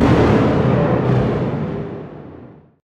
magic_thunder.ogg